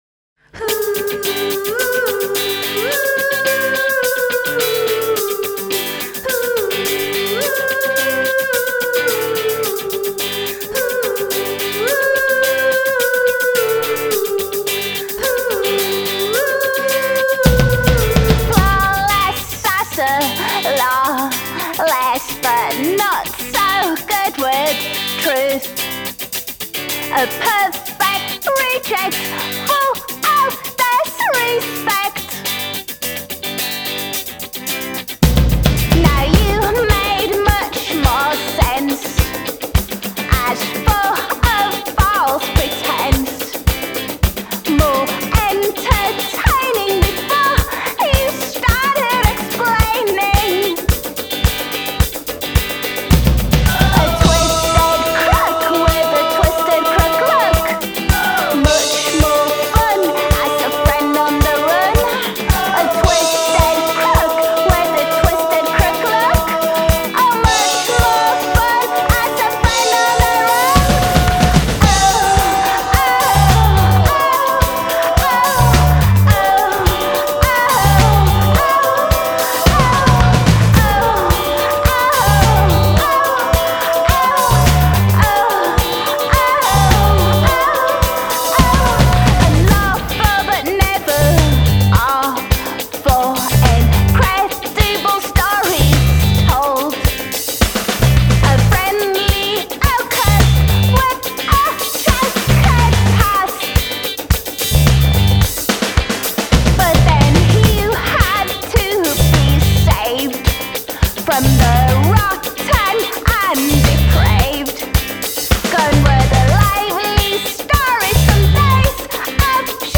post-punk
sembra prevalere l'anima più irruenta, quasi rabbiosa